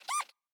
assets / minecraft / sounds / mob / fox / idle3.ogg